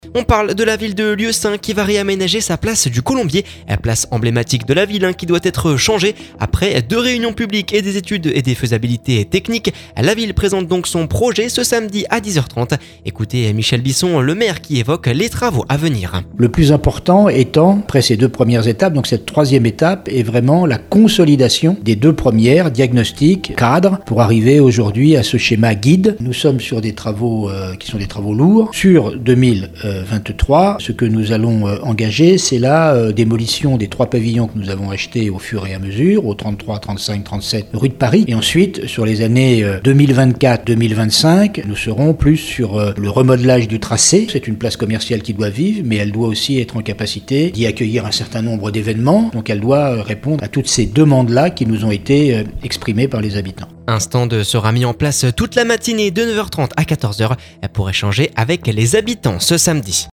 Michel Bisson le maire évoque les travaux à venir…